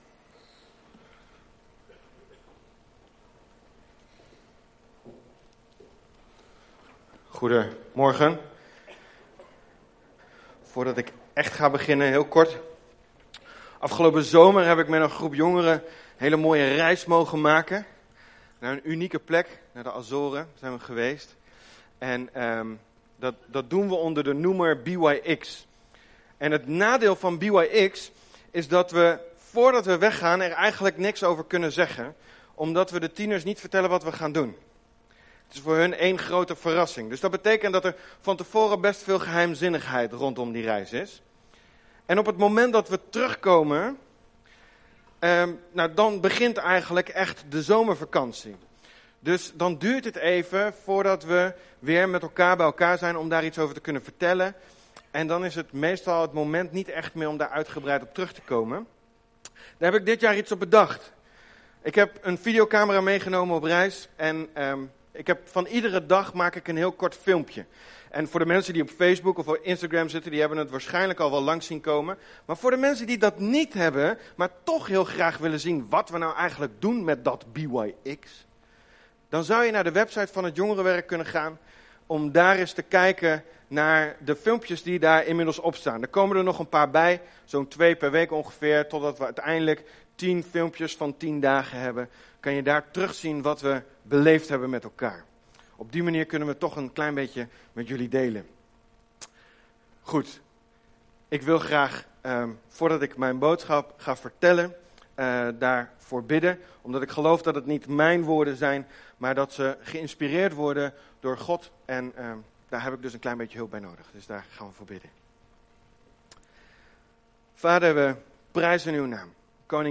We komen elke zondagmorgen bij elkaar om God te aanbidden.